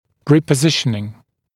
[ˌriːpə’zɪʃnɪŋ][ˌри:пэ’зишнин]репозиция, повторное позиционирование